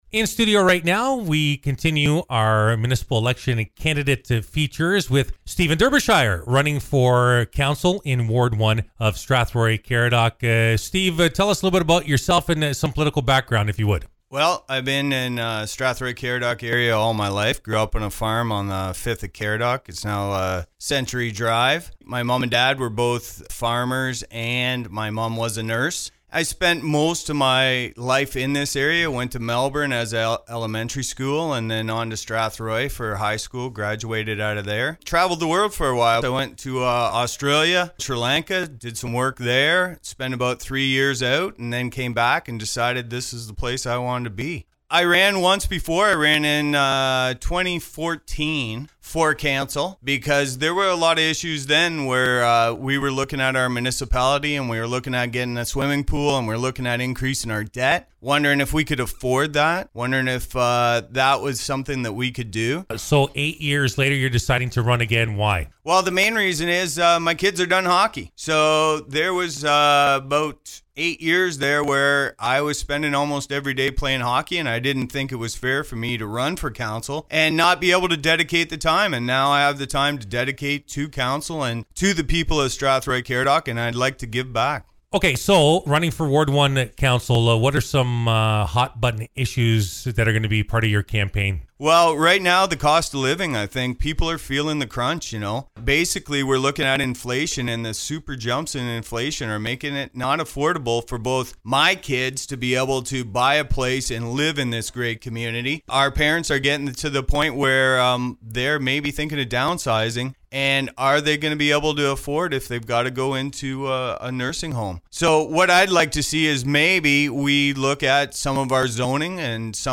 Here is the complete interview